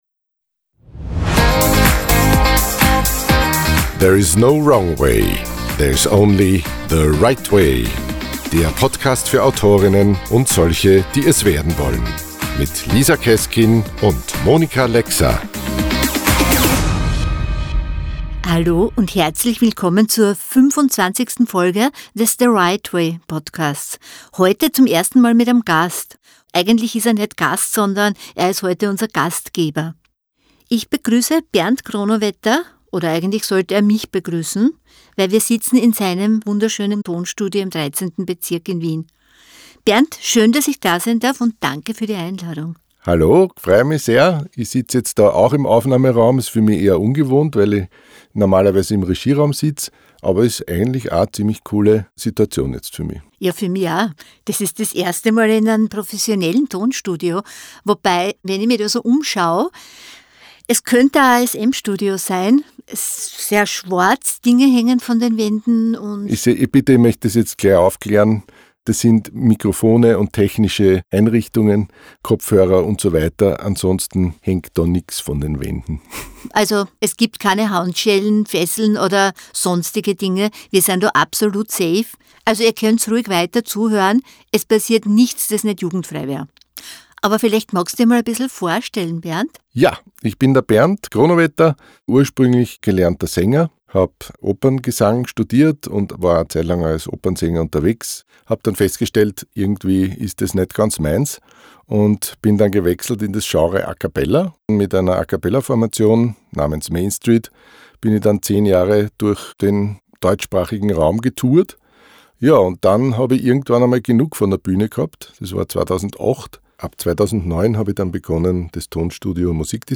Zwischen schwarzen Wänden und bestem Kaffee haben wir für euch festgehalten, wie es in einem Tonstudio zugeht. Und falls euch die Qualität dieser Folge angenehm auffällt: Das passiert, wenn man den Profi mischen lässt!